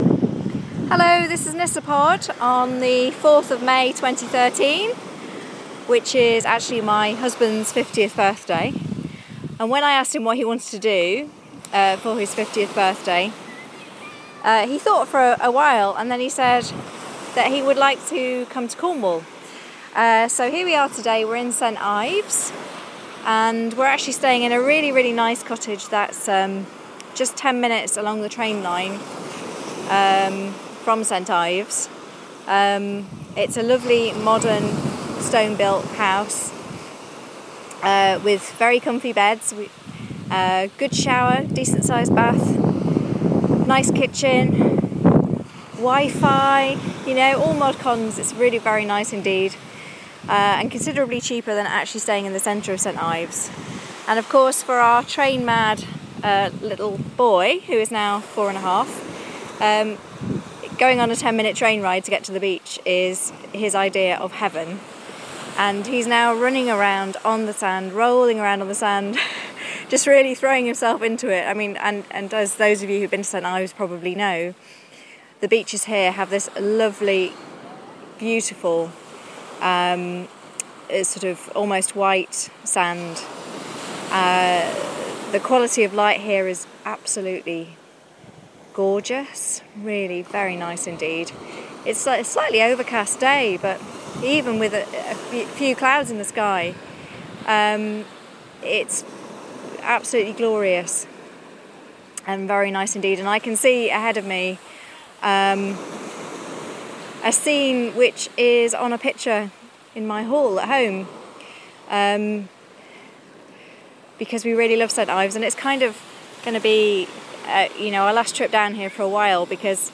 Audio postcard from St Ives, Cornwall